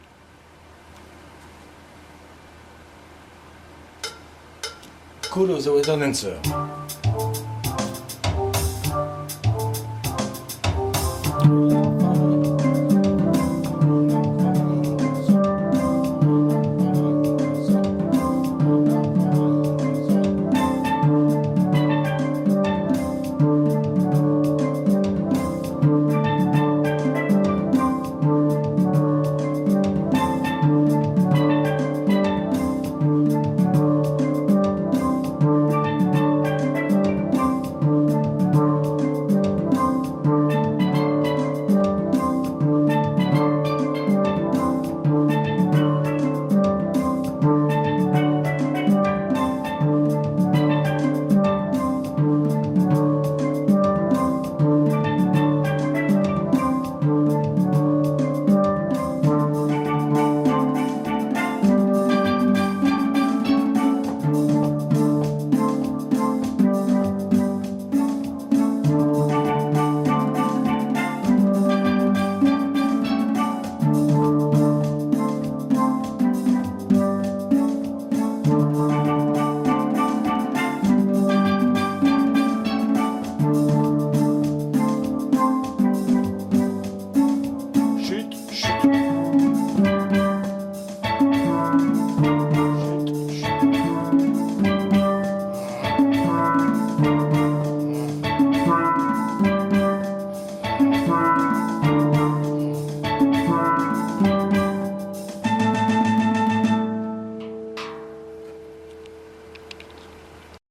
GUITAR CALL OF THE WATER DANCER FILM .mp3